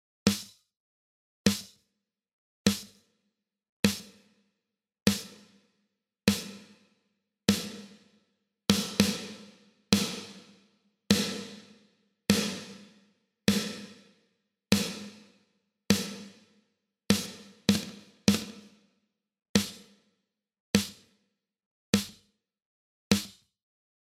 Ich habe hier mal 3 ValhallaPlates zusammen genommen und den Return ein- und ausgefadet. Kein EQ oder was anderes auf den Returns...